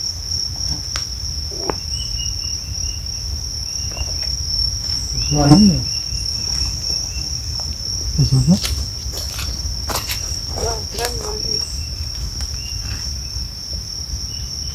Suirirí Silbón (Sirystes sibilator)
Nombre en inglés: Sibilant Sirystes
Localización detallada: Misión de Loreto
Condición: Silvestre
Certeza: Fotografiada, Vocalización Grabada